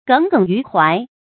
耿耿于懷 注音： ㄍㄥˇ ㄍㄥˇ ㄧㄩˊ ㄏㄨㄞˊ 讀音讀法： 意思解釋： 耿耿；形容有心事。